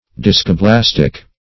Search Result for " discoblastic" : The Collaborative International Dictionary of English v.0.48: Discoblastic \Dis`co*blas"tic\, a. [Gr.